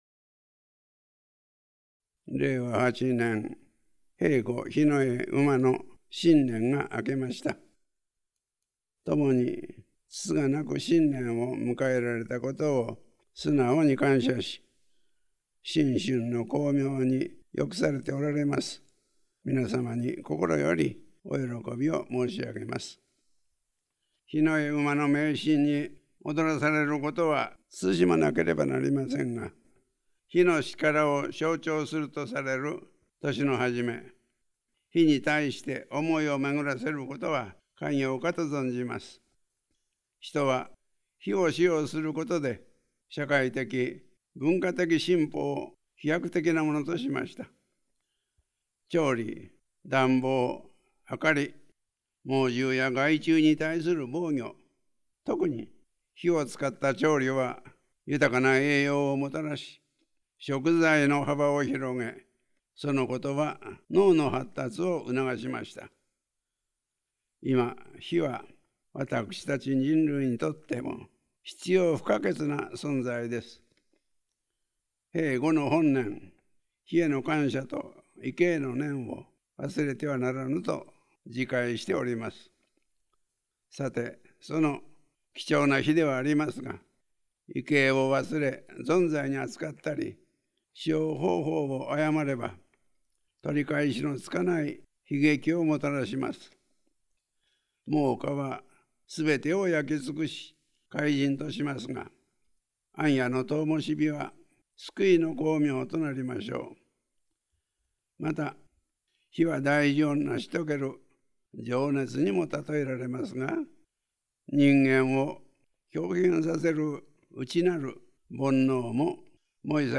曹洞宗管長　南澤道人
ラジオNIKKEI　2026.1.1　放送　「声の年賀」より